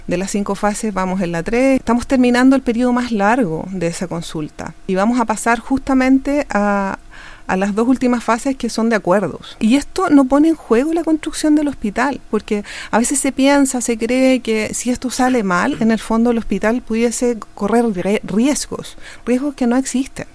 En conversación con Radio Bío Bío, la Seremi de Salud de Los Ríos, Ivonne Arre, detalló que comenzará la tercera etapa, de un total de cinco y que está vinculada a los acuerdos de la consulta.